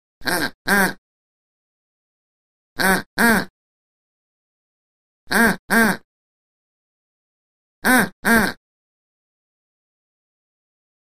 Duck Calls - 4 Effects; Short Duck Calls.